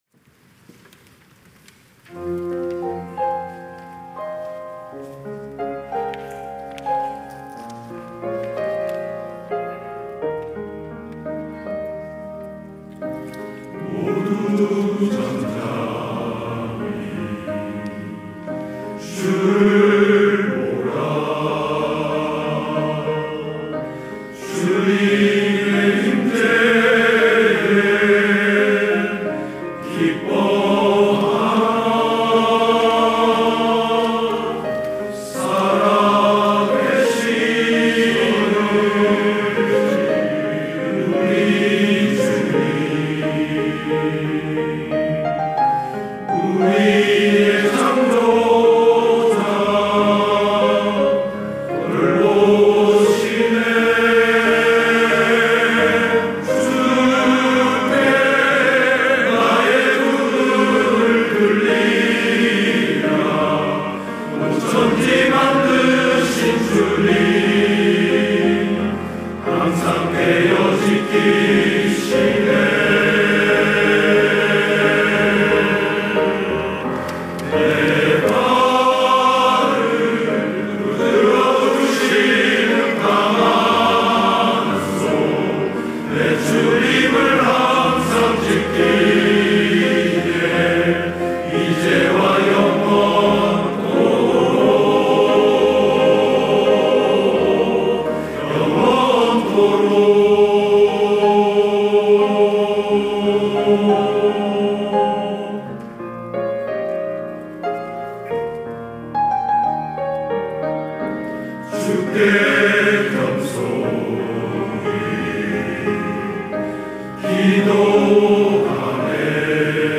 찬양대 남선교회